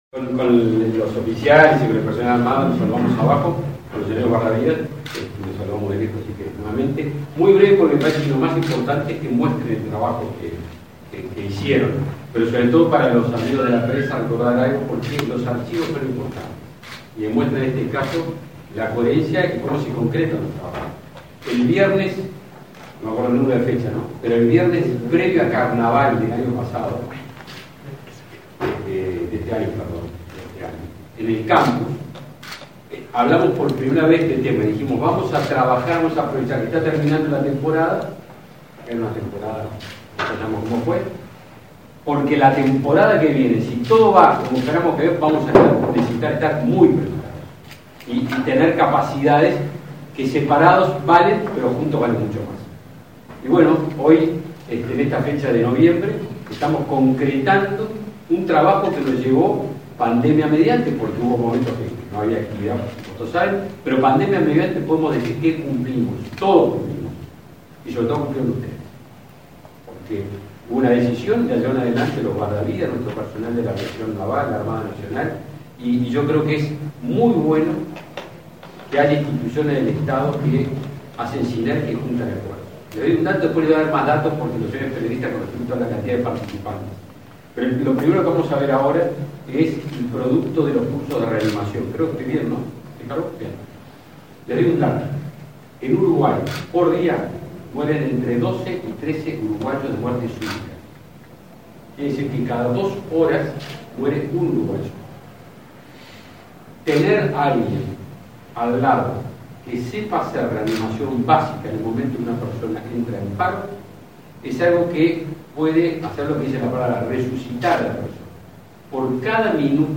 Palabras del ministro de Defensa Nacional, Javier García
El ministro Javier García visitó, este 17 de noviembre, la base Carlos Curbelo de la Armada Nacional, en Laguna del Sauce. En la oportunidad, realizó un balance de las tareas preparatorias para la aplicación del convenio entre el ministerio y la Intendencia de Maldonado, que implica el trabajo en conjunto de la Aviación Naval y el servicio de guardavidas, en la próxima temporada.